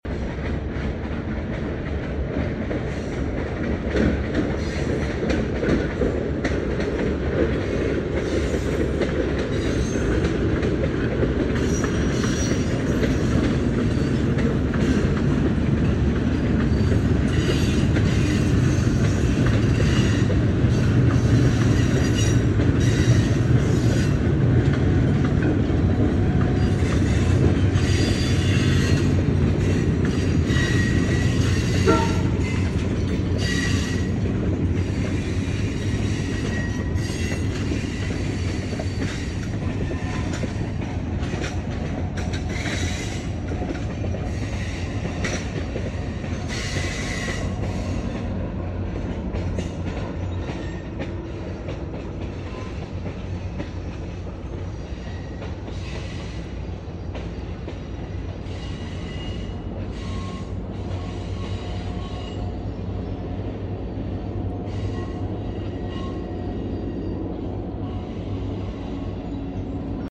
16 times every weekend the sounds of this train, drown out the river sounds. But I love the train sounds and the peaceful river sounds equally 🥰 And every time it comes by